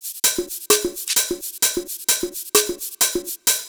Downtown House/Loops/Drum Loops 130bpm